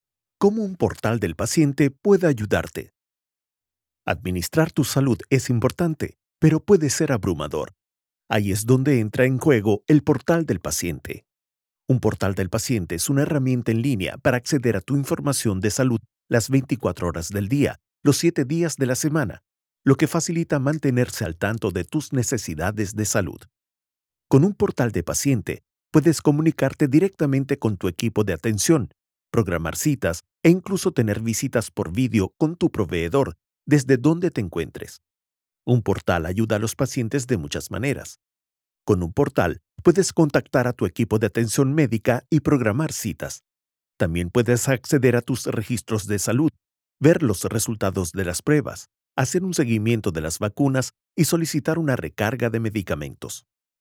Kommerziell, Junge, Cool, Vielseitig, Corporate
Erklärvideo